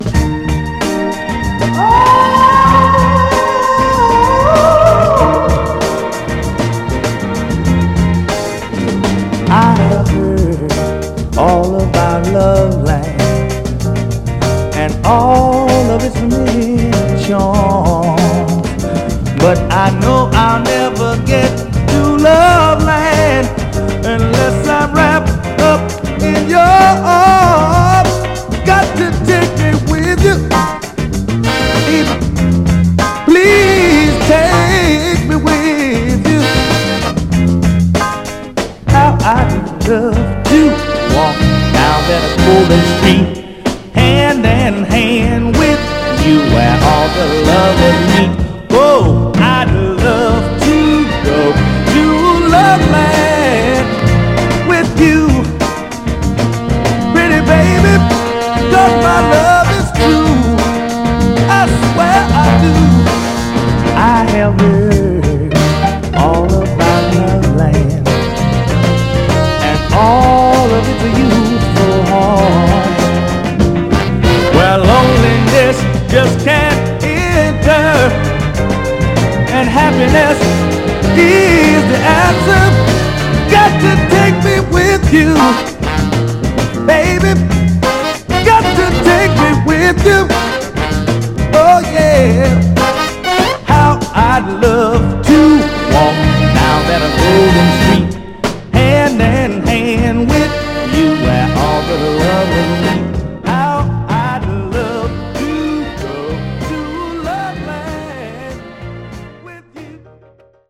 盤はエッジ中心に細かいスレ、ごく細かいヘアーラインキズ箇所ありますが、グロスが残ありプレイ良好です。